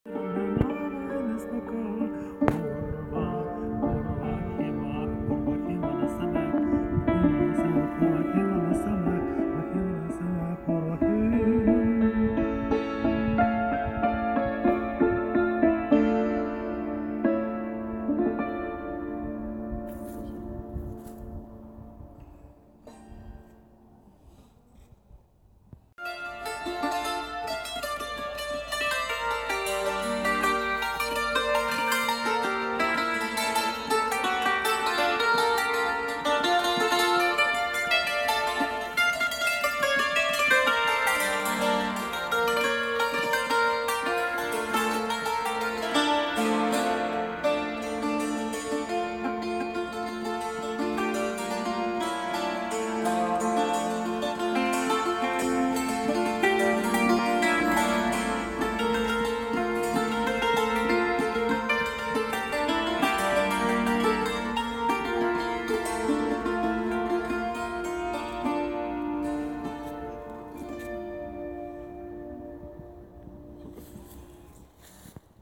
Tsymbali
Ukrainian instrument tsymbali played by local musician at Pirogovo, Kyiv